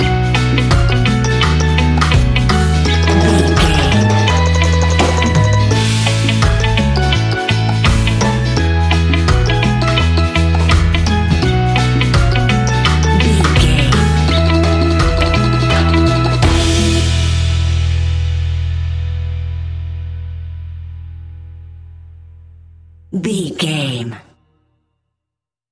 Ionian/Major
Slow
steelpan
happy
drums
percussion
bass
brass
guitar